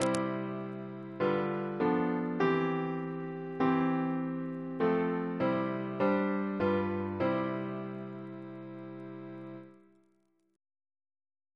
Single chant in C Composer: Theodore Aylward (1730-1801) Reference psalters: OCB: 259